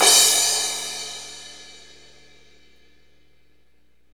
Index of /90_sSampleCDs/Northstar - Drumscapes Roland/CYM_Cymbals 1/CYM_F_S Cymbalsx